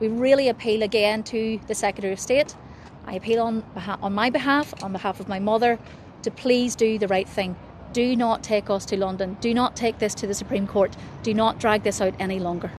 made this appeal outside court